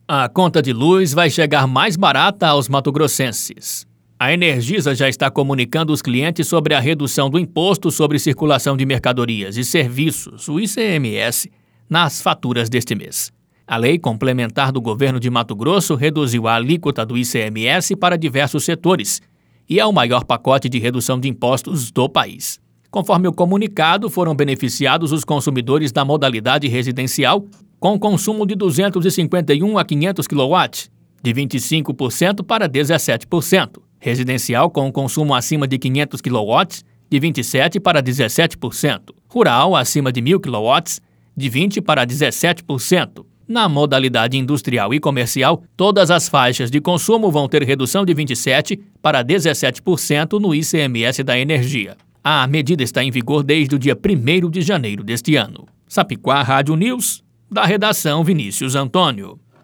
Boletins de MT 15 fev, 2022